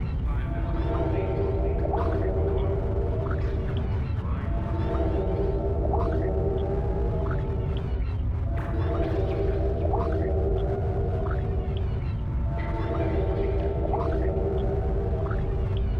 描述：带锁的门
Tag: 120 bpm Cinematic Loops Fx Loops 2.93 MB wav Key : Unknown